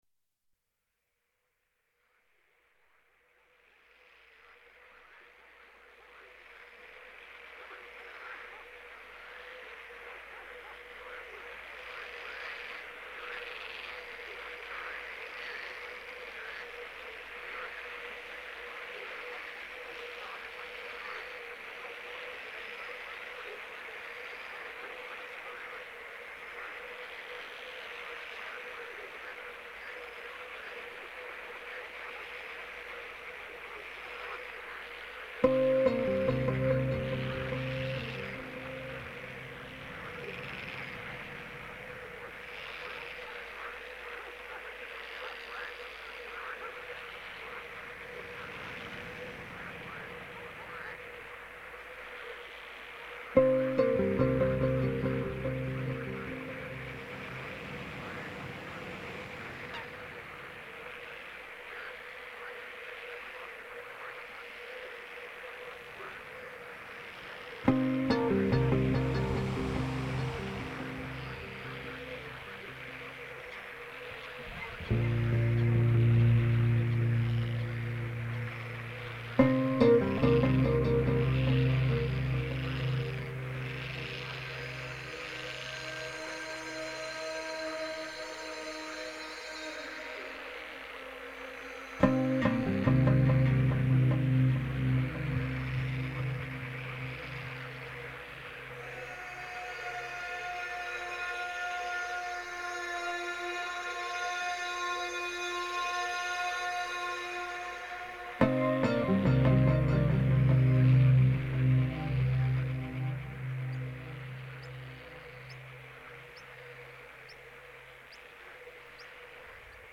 muzyka, instrumenty
teksty, głosy